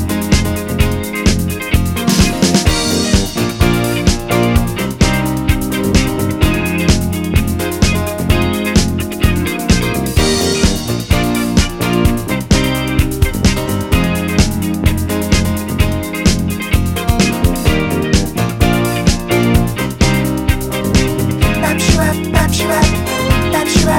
No Bass Guitar, No Guitar, No Drumkit Disco 3:37 Buy £1.50